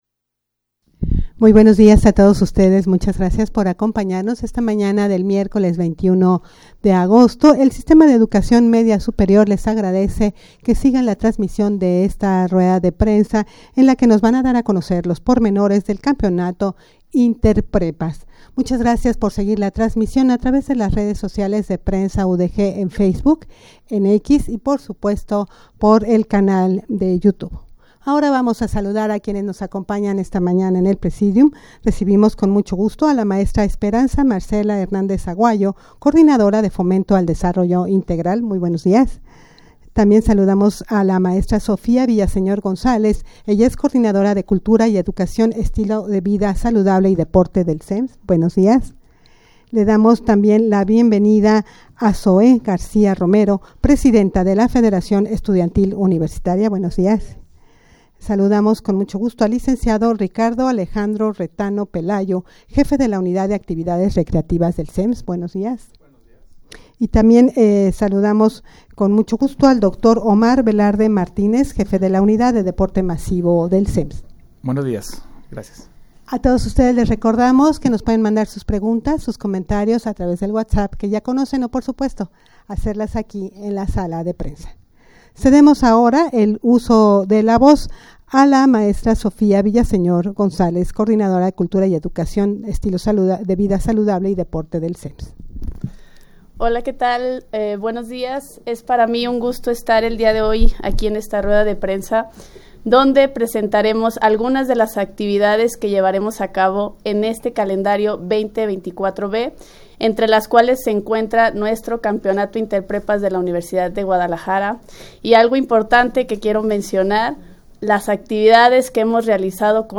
Audio de la Rueda de Prensa
rueda-de-prensa-para-dar-a-conocer-los-pormenores-del-campeonato-interprepas.mp3